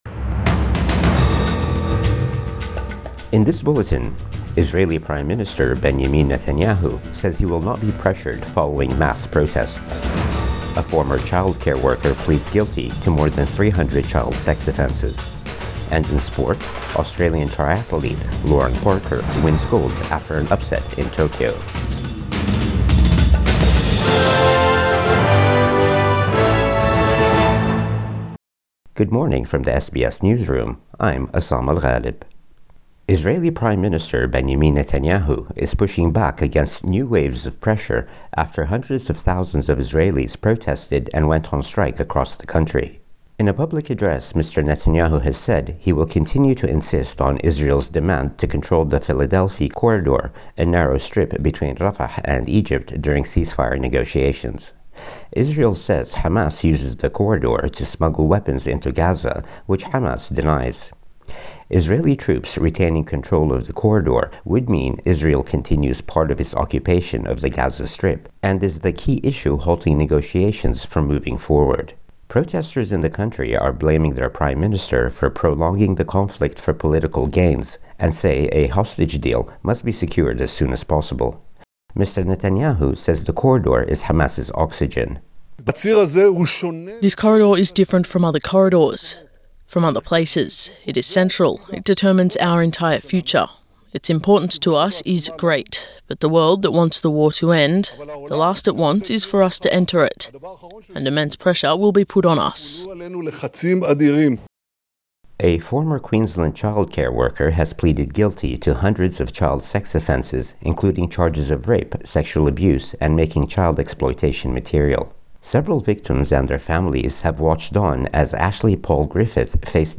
Morning News Bulletin 3 September 2024